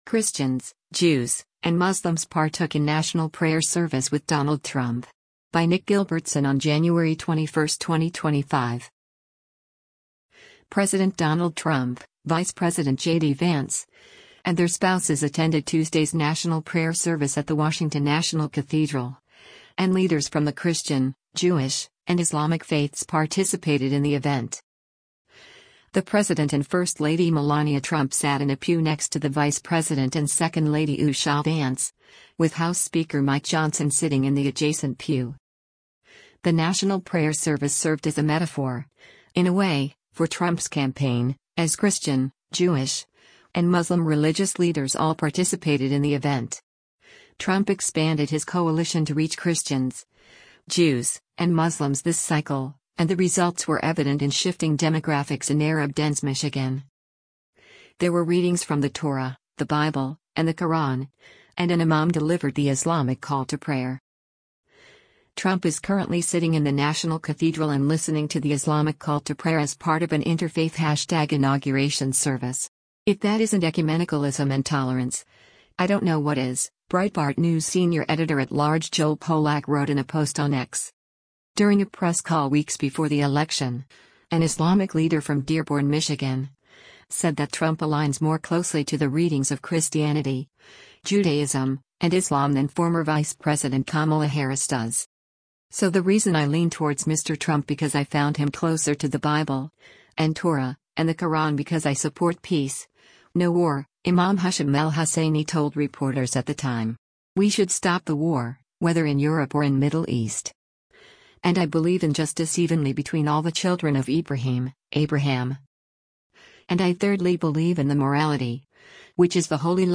While there were positive moments during the National Prayer Service, the liberal anti-Trump Episcopal Bishop of Washington, DC, Mariann Budde, delivered a highly partisan sermon, calling on Trump to have “mercy” for LGBTQ children.